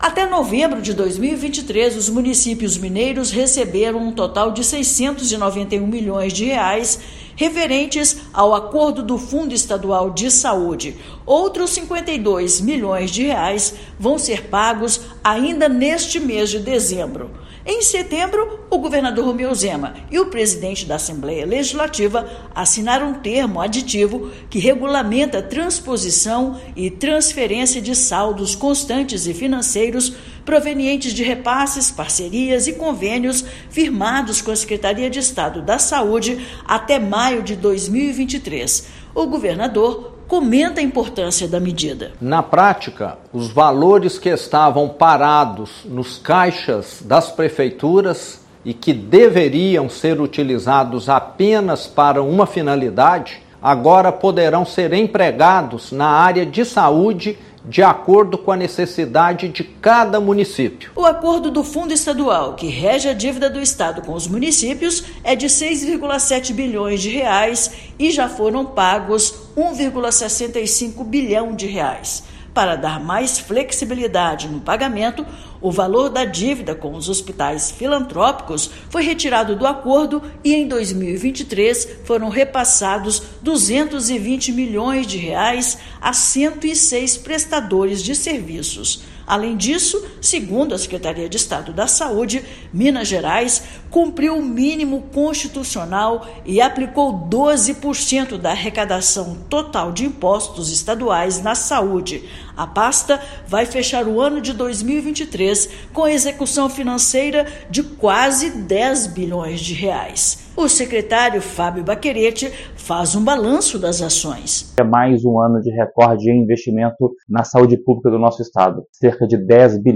Pasta fecha o ano com execução financeira total de R$ 9,9 bilhões. Ouça matéria de rádio.